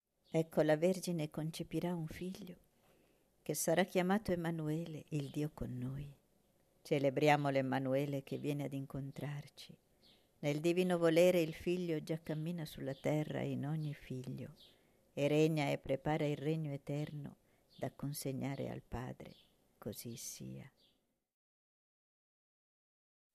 Preghiera mp3